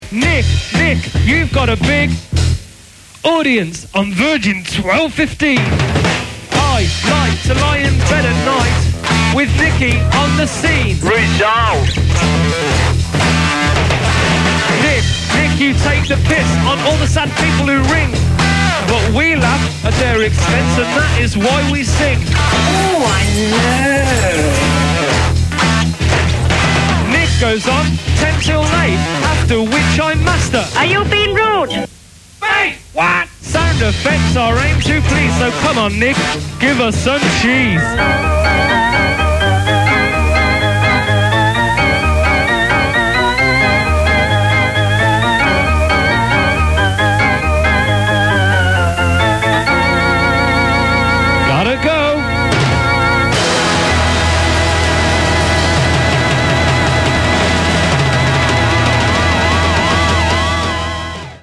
These were made by fans of his show.